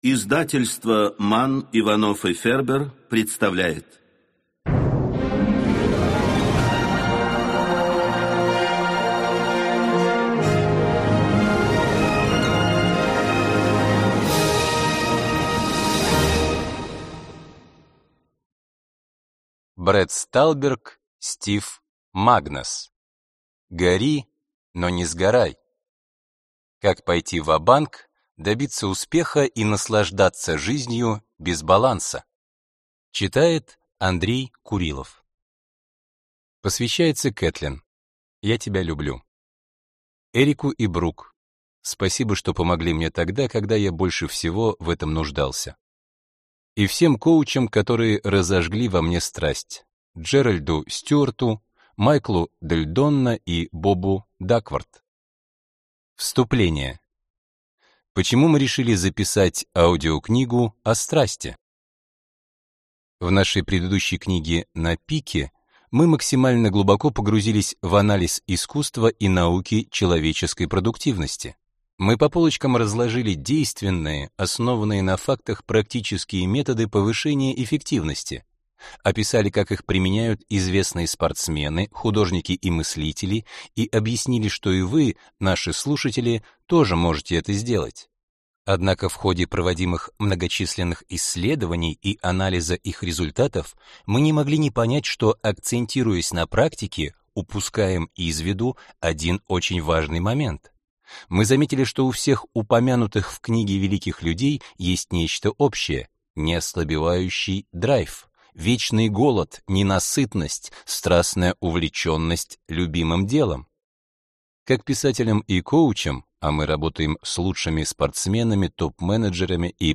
Аудиокнига Гори, но не сгорай | Библиотека аудиокниг